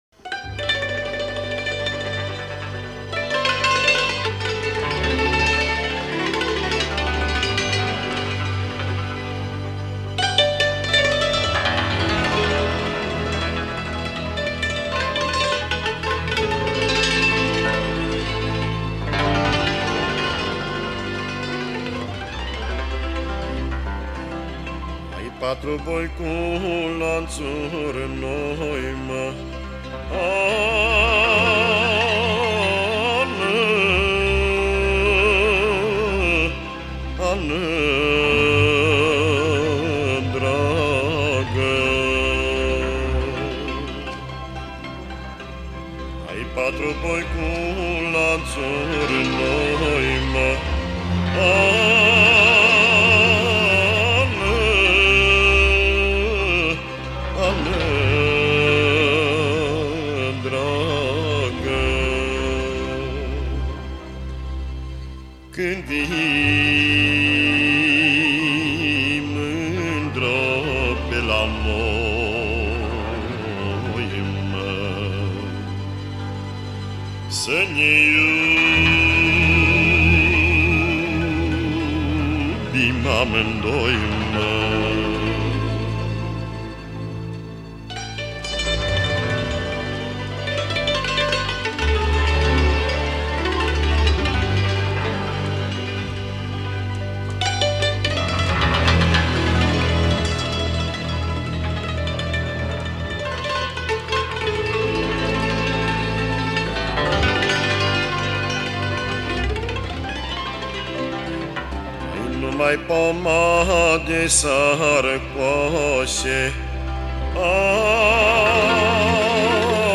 Înregistrări de arhivă